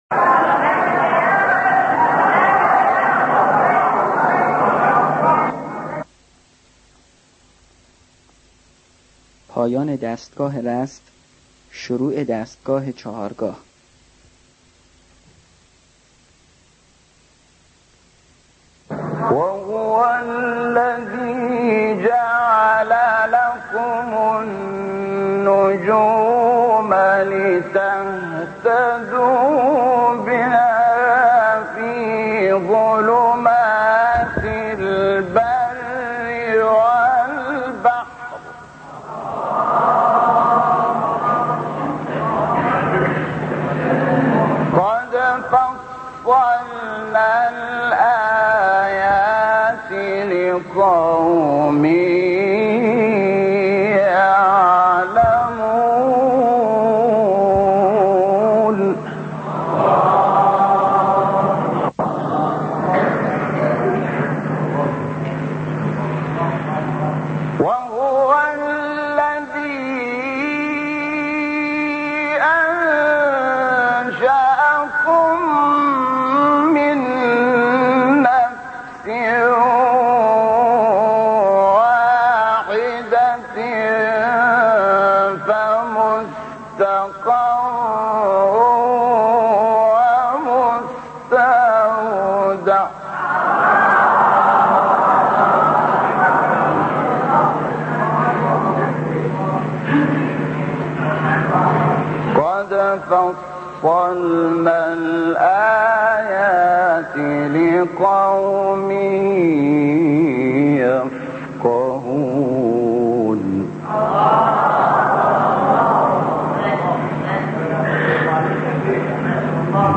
سایت-قرآن-کلام-نورانی-شحات-چهارگاه.mp3